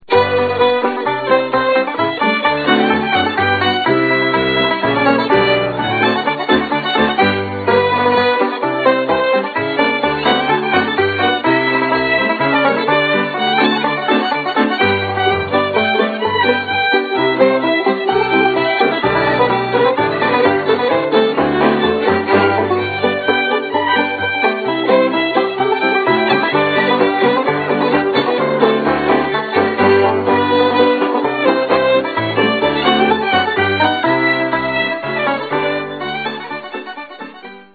fiddle
So it is that the two polkas on track 1
where almost all the tracks were recorded, live.